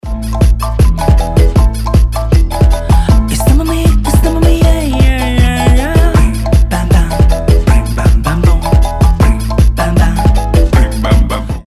рэп
ритмичные
jersey club
веселые